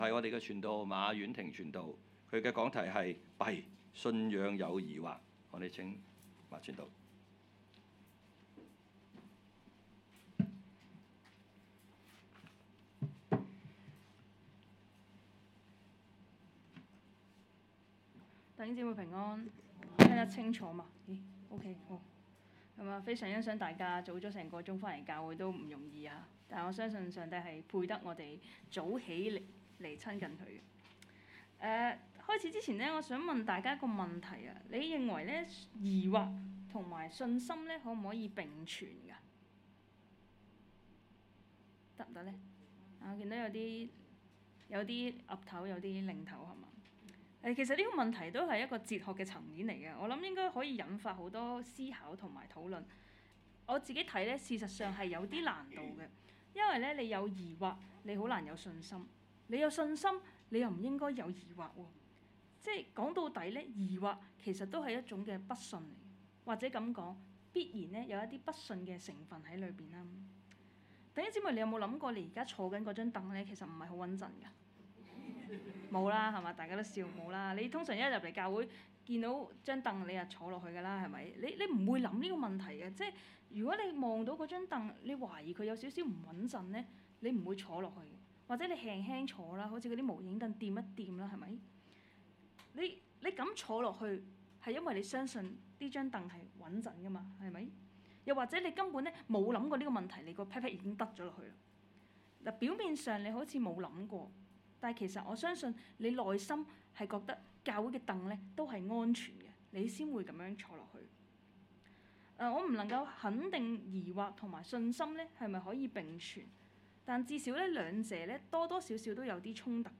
講道 ：弊！ 信仰有疑惑！